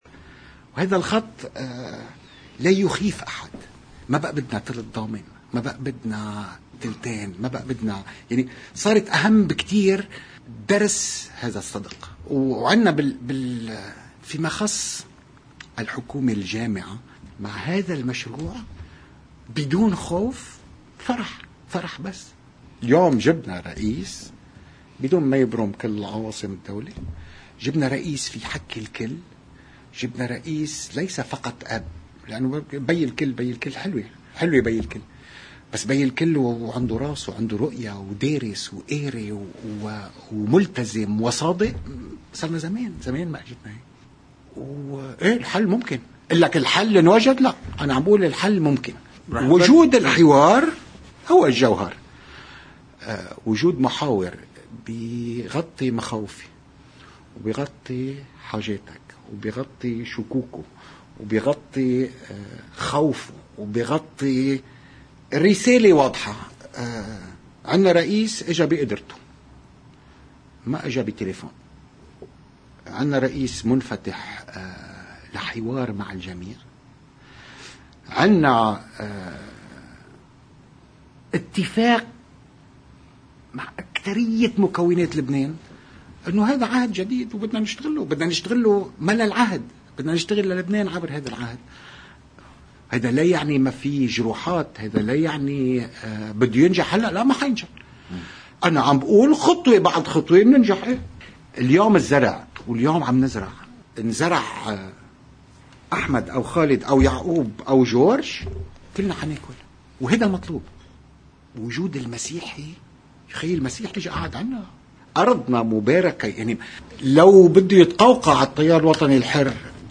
مقتطفات من حديث الوزير السابق يعقوب الصراف لقناة الـ”OTV”: